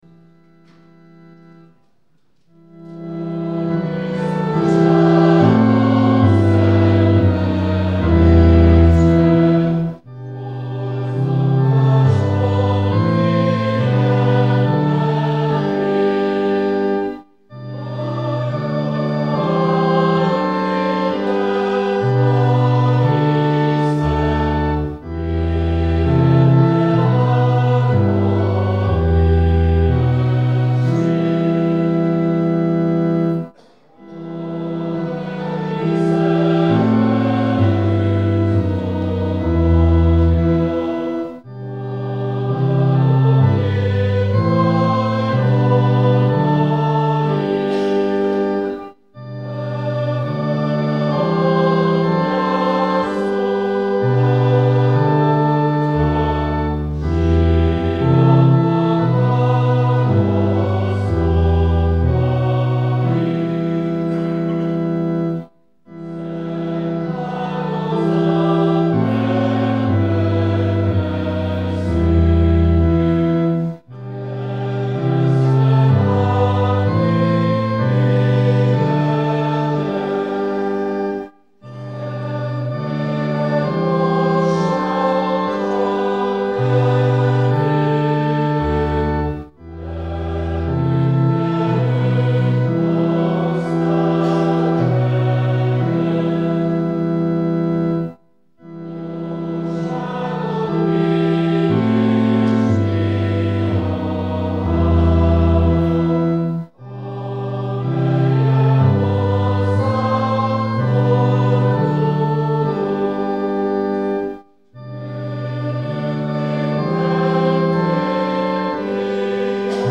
Igehirdetések2005. január 1. - 2005. december 31.